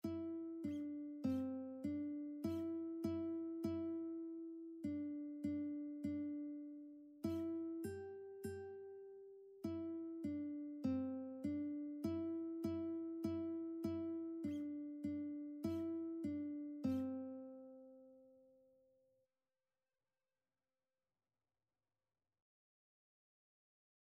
Childrens
4/4 (View more 4/4 Music)